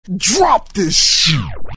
Tm8_Chant14.mp3